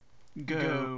vad-go-stereo-44100.wav